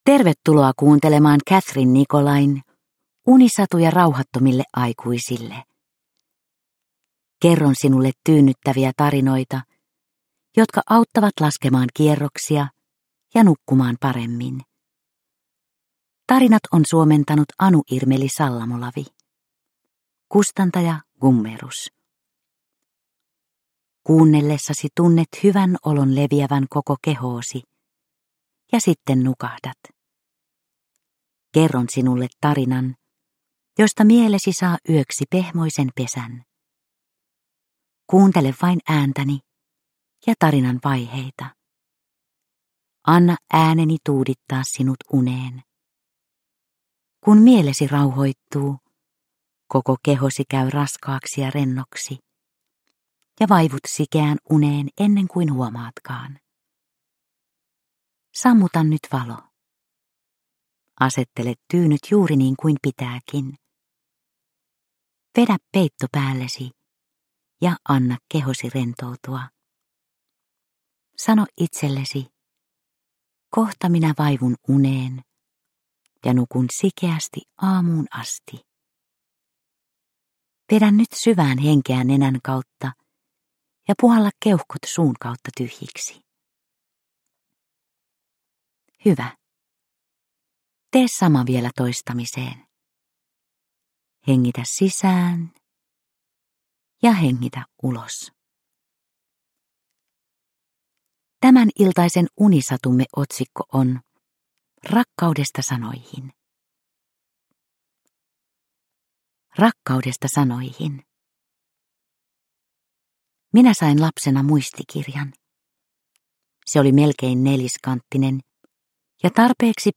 Unisatuja rauhattomille aikuisille 17 - Rakkaudesta sanoihin – Ljudbok – Laddas ner
Produkttyp: Digitala böcker